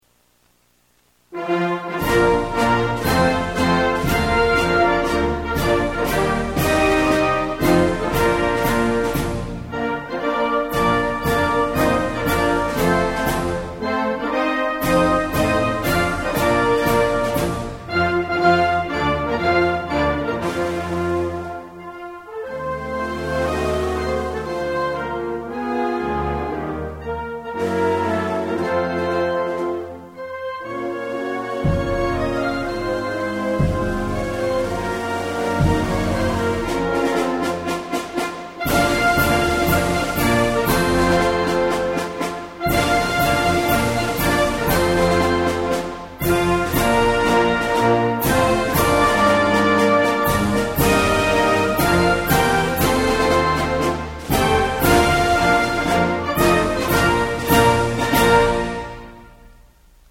Accueil émouvant : à 8 heures, une musique militaire vient sur le quai, au pied du paquebot et joue :